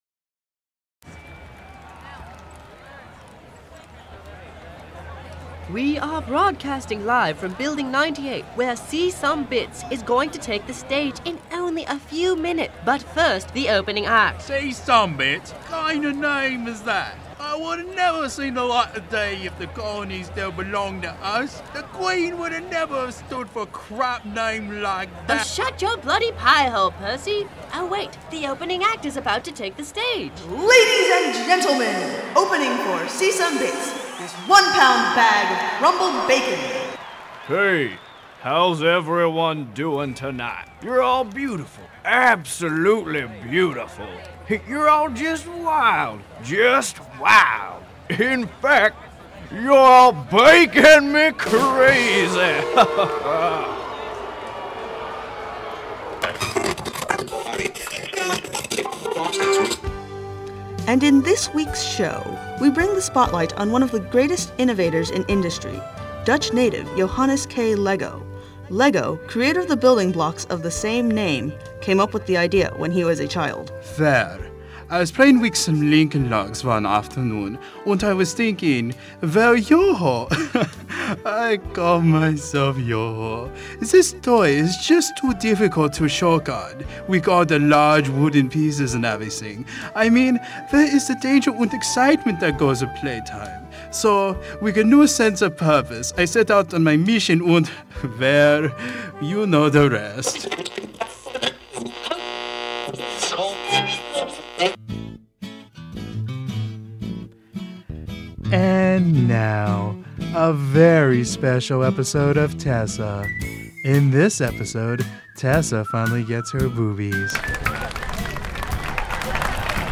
using Audacity Freeware I had to scale back on the sound quality to get it on here
This is an audio preshow for my improv Group's live show 11/14/05.